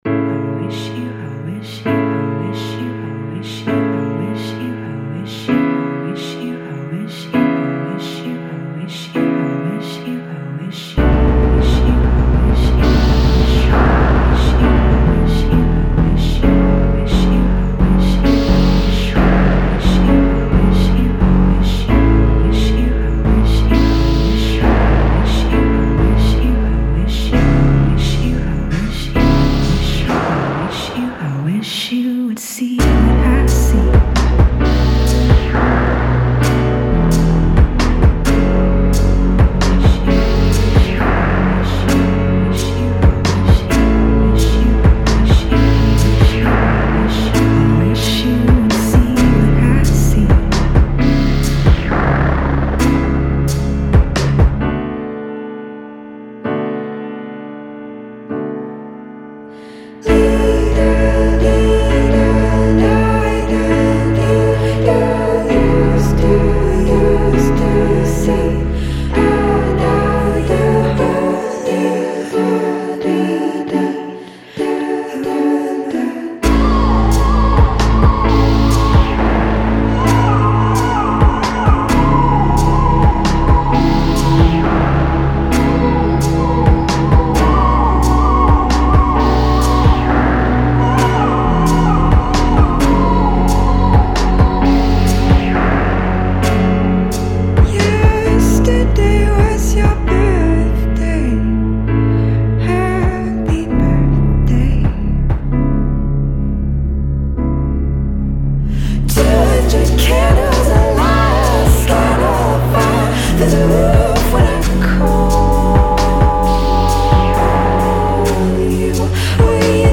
Graceful and balanced